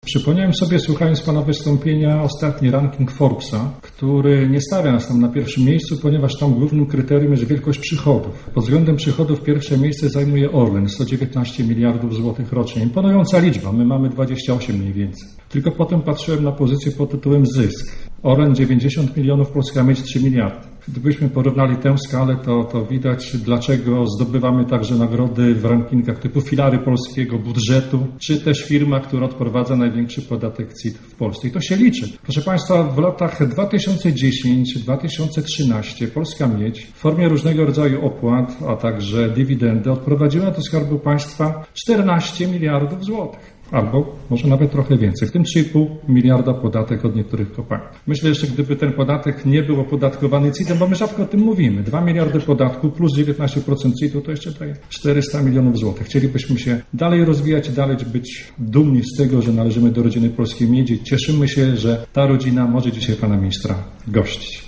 ale też parlamentarzystów, wojewody i marszałka, którzy siedzieli na sali podczas hutniczej gali.